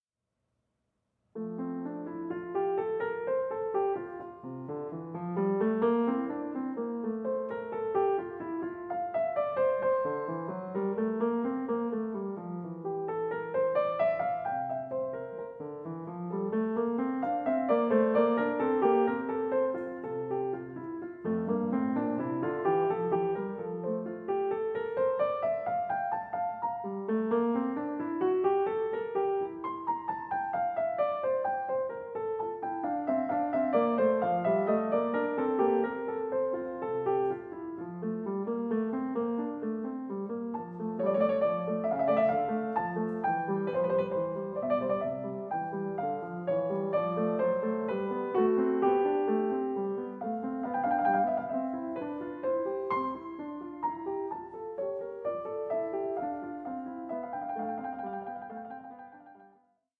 Allegro 4:21